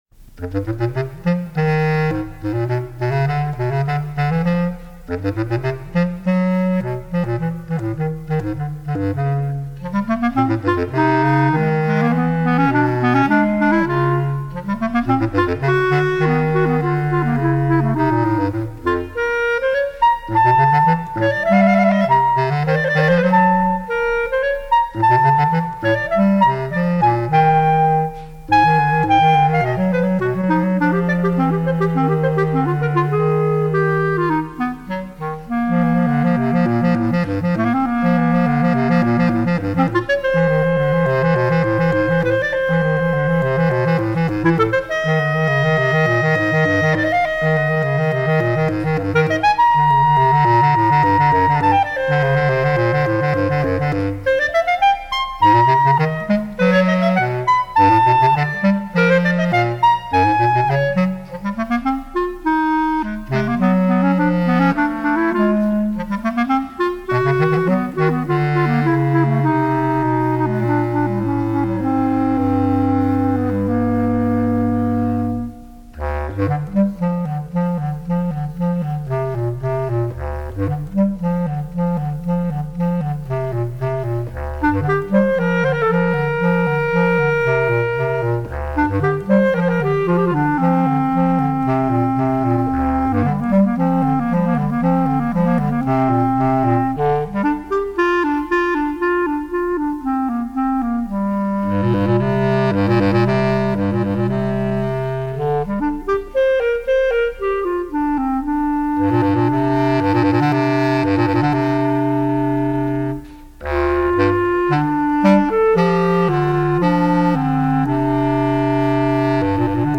04 Duet for Single Reeds | Berklee Archives